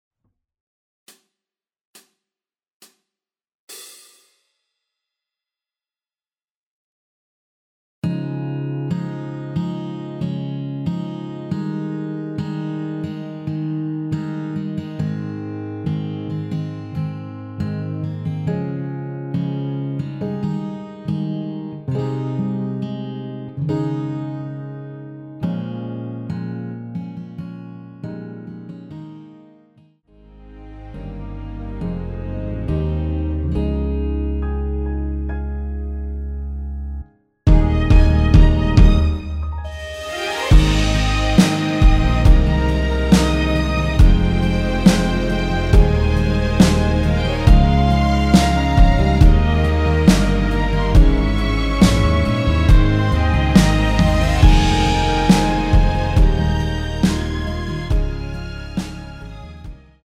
전주 없이 시작하는 곡이라서 시작 카운트 만들어놓았습니다.(미리듣기 확인)
원키(1절앞+후렴)으로 진행되는 MR입니다.
Ab
앞부분30초, 뒷부분30초씩 편집해서 올려 드리고 있습니다.